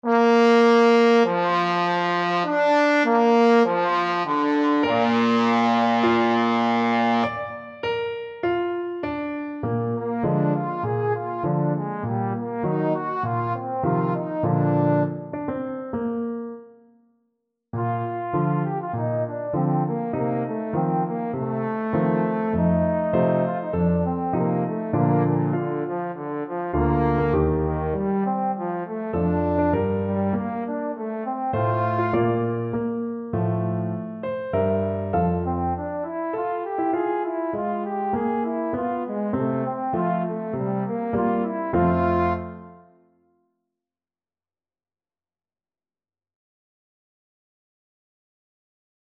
Trombone
Bb major (Sounding Pitch) (View more Bb major Music for Trombone )
Andante
4/4 (View more 4/4 Music)
Classical (View more Classical Trombone Music)